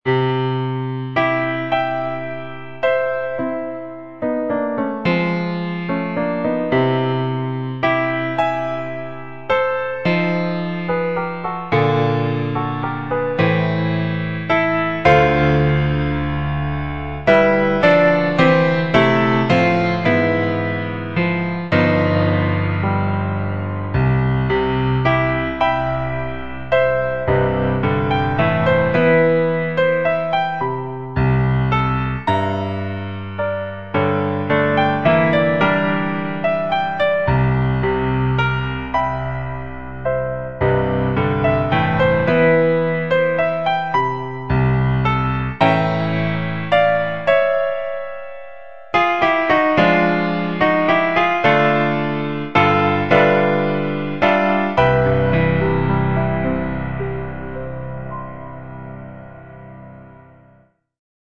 Empreintes Debussystes, for piano, in C major